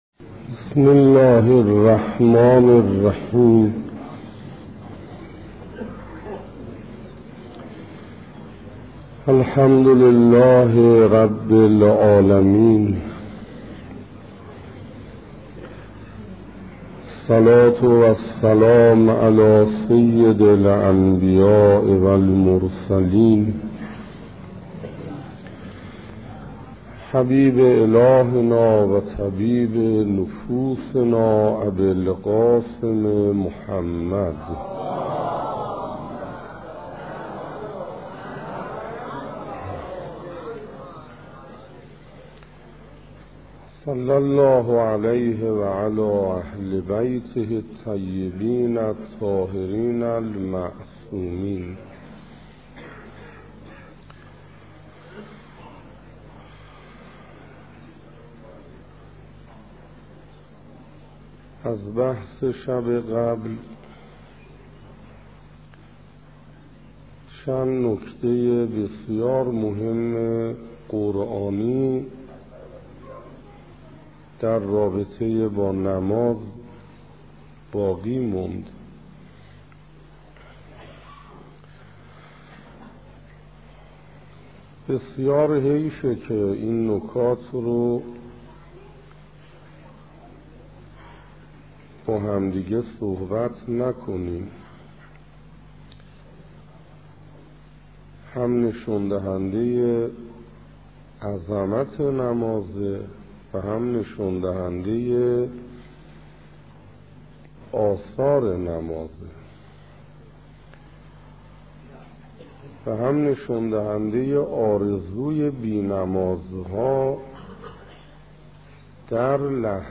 گلچین سخنرانی ها - گلچین سخنرانی:نماز،امر به معروف - 0 -